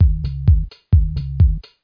tzwdrum4.mp3